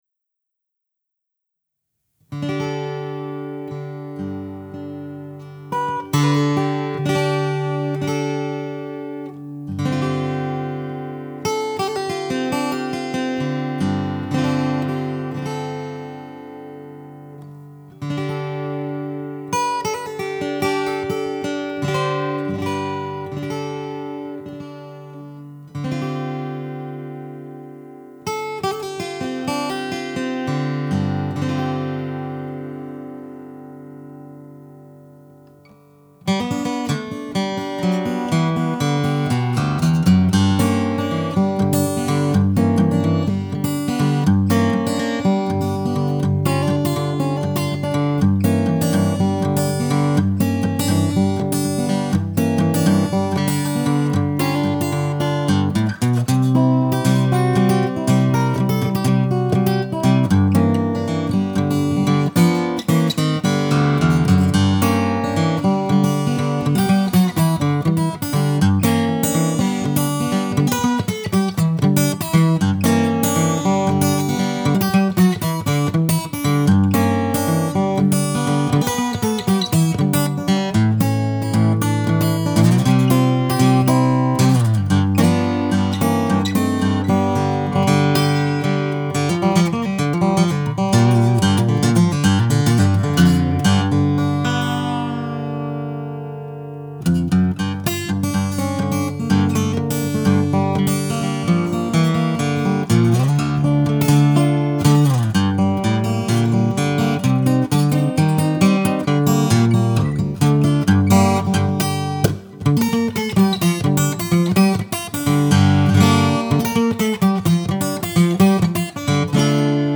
I've posted this in the past, its an R121 and R84 in a Blumlin pair, not vocals, but I think its a good representation of what they sound like...